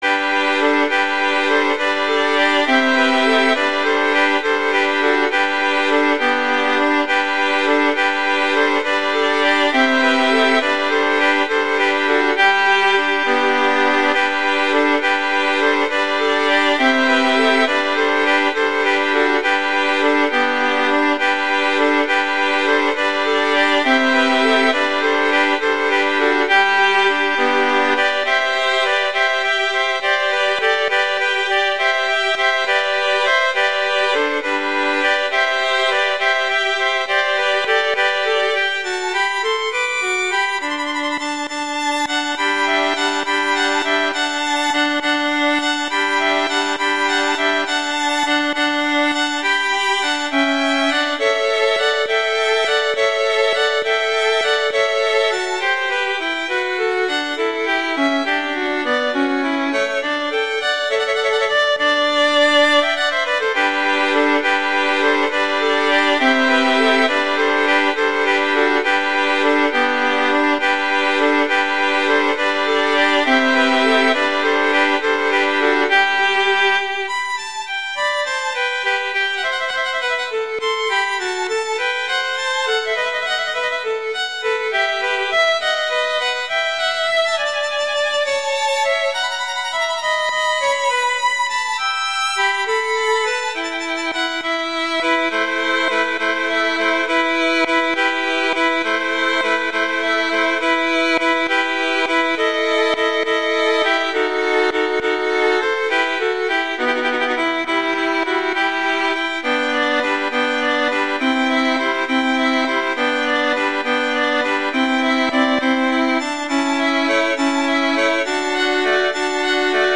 two violins
classical, french